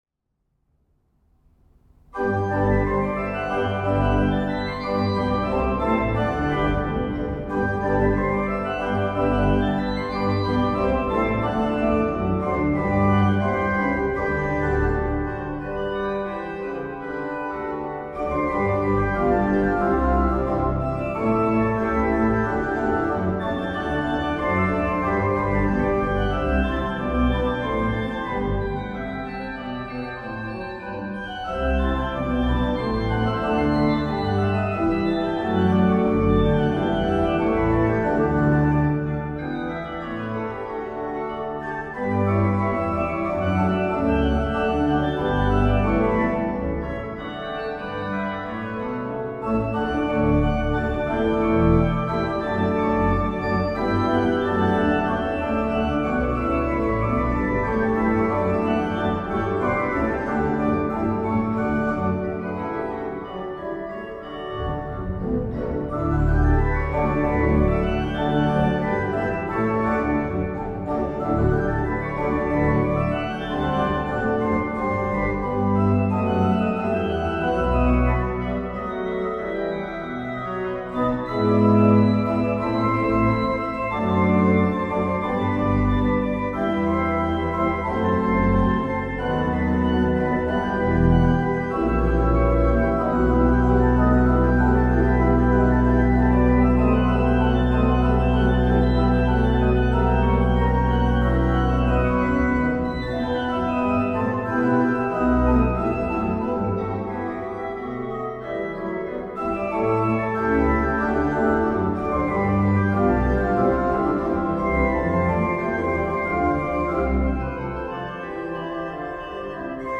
an der Kleuker-Orgel der Petrikirche Petershagen